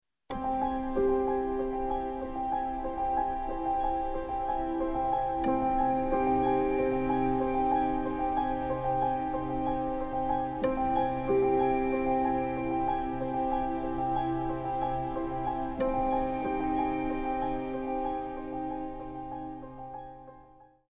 6. Passer de mode majeur à mode mineur :